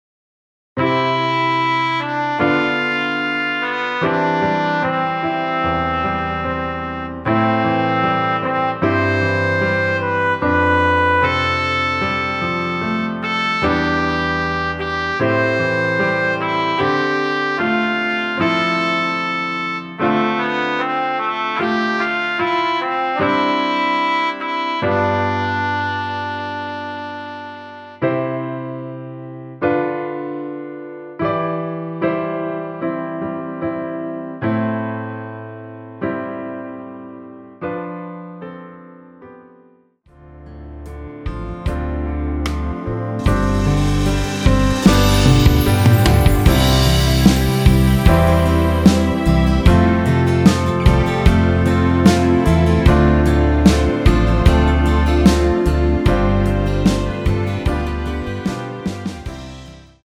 앞부분30초, 뒷부분30초씩 편집해서 올려 드리고 있습니다.
중간에 음이 끈어지고 다시 나오는 이유는
축가 MR